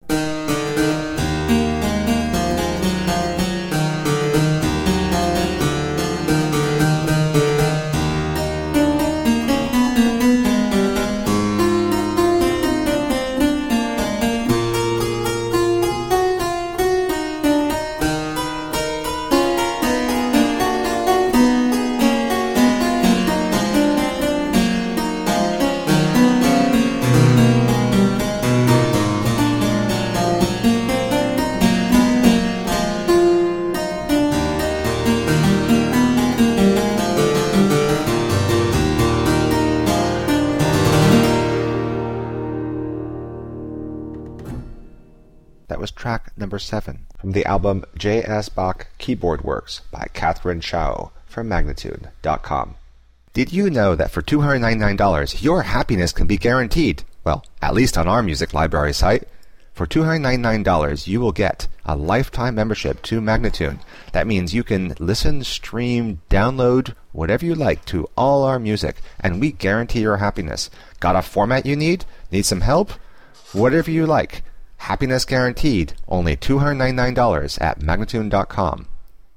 three different beautiful harpsichords
Classical, Baroque, Instrumental, Harpsichord